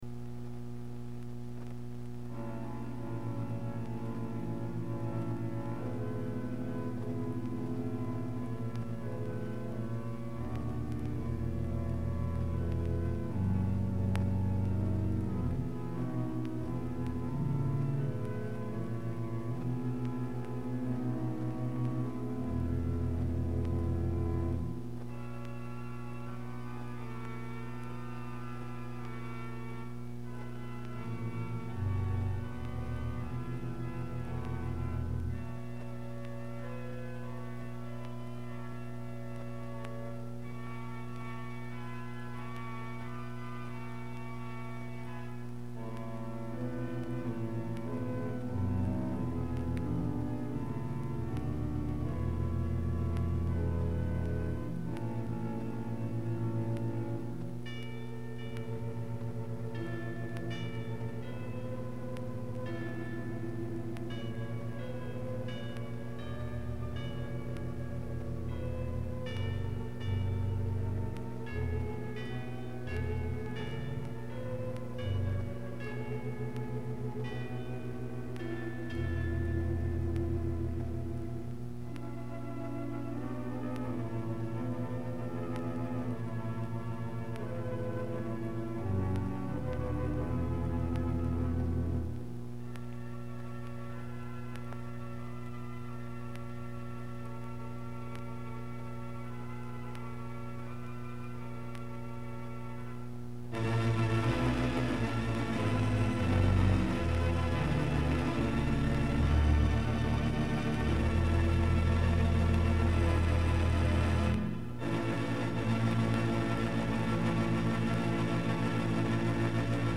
Pipe Organ Music